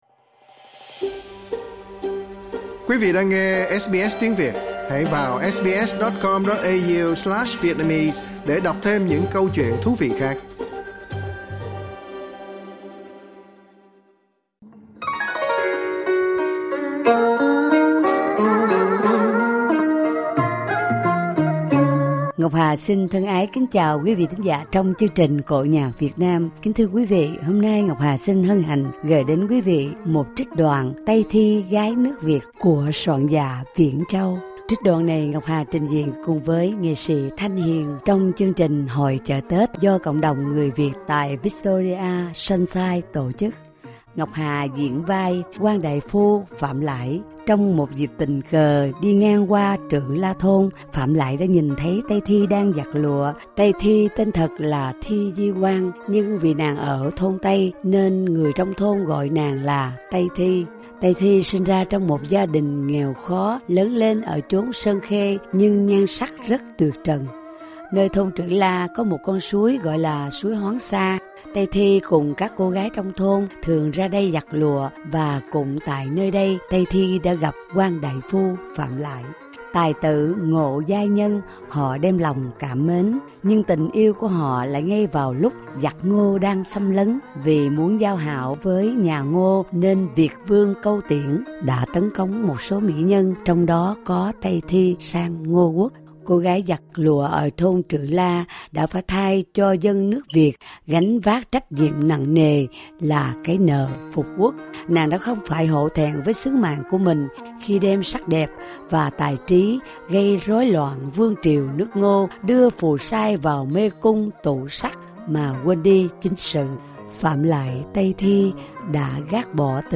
ở Hội chợ Tết tại Sunshine, Victoria